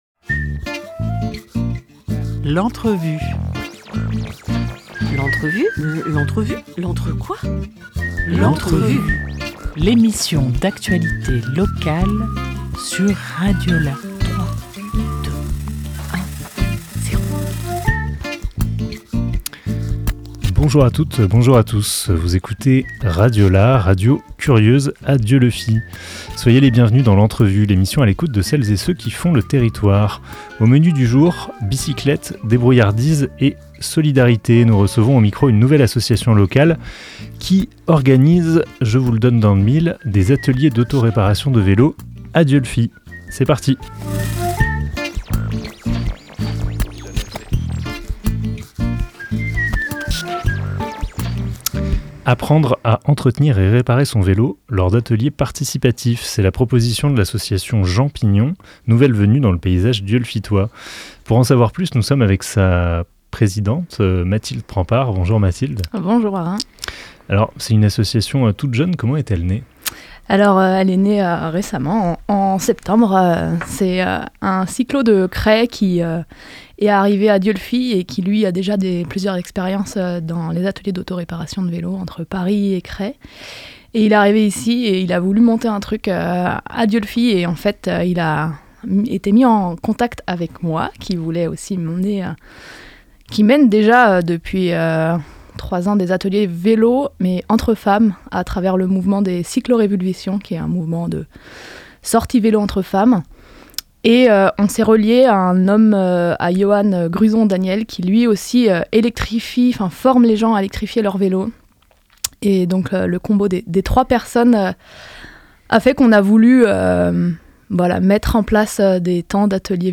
4 février 2025 11:06 | Interview